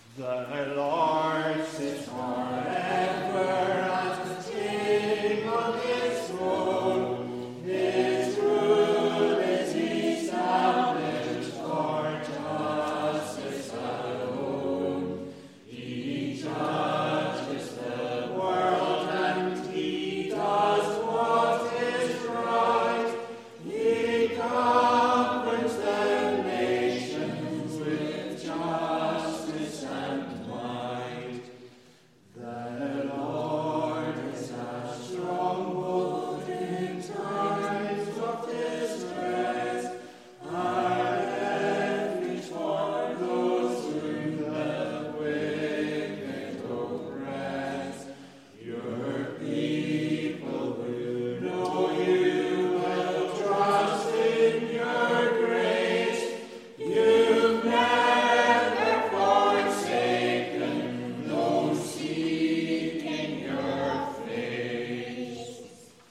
Congregational Psalm Singing